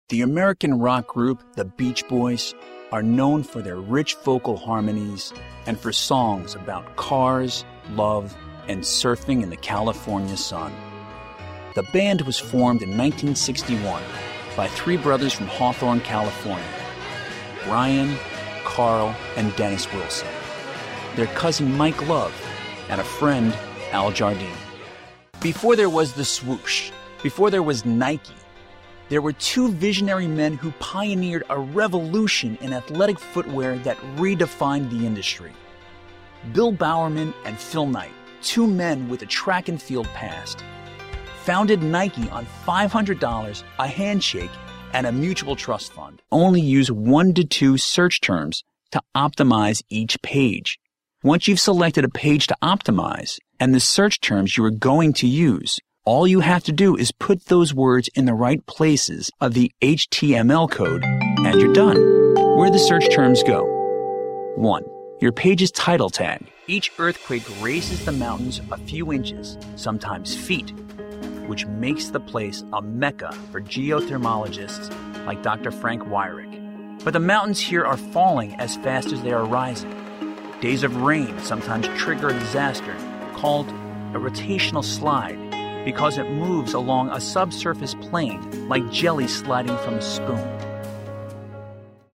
Adult, Young Adult
mid atlantic
standard us
documentary
friendly
well spoken